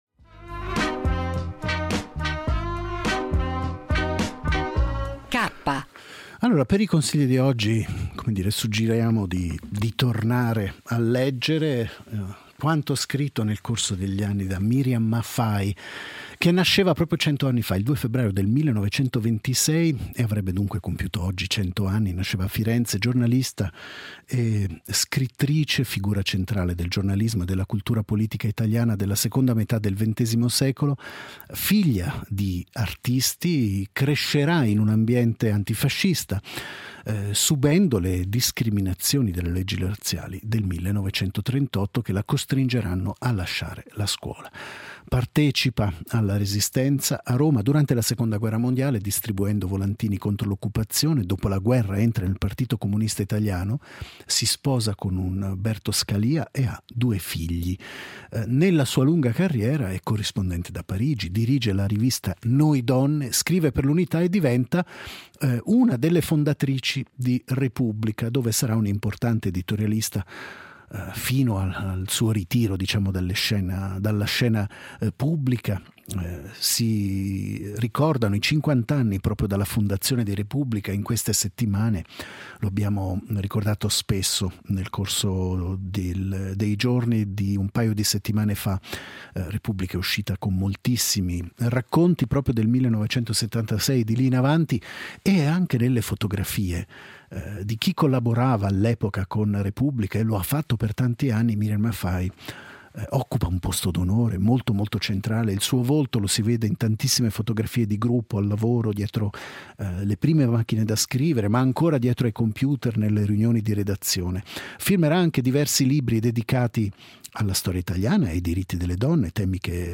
Abbiamo riascoltato estratti da una lunga intervista proposta da Storia nel 2009